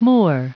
Prononciation du mot moor en anglais (fichier audio)
Prononciation du mot : moor
moor.wav